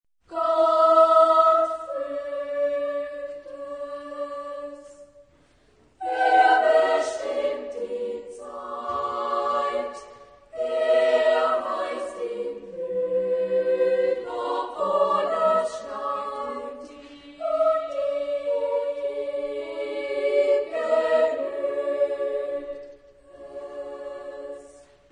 Genre-Stil-Form: geistlich ; Lied
Chorgattung: SSAA  (4 Kinderchor ODER Frauenchor Stimmen )
Tonart(en): D dorisch
Aufnahme Bestellnummer: 3.Deutscher Chorwettbewerb, 1990